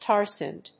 • IPA(key): /ˈtɑː(ɹ)sænd/